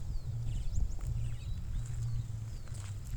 Golondrina Negra (Progne elegans)
Nombre en inglés: Southern Martin
Localización detallada: Costanera
Condición: Silvestre
Certeza: Vocalización Grabada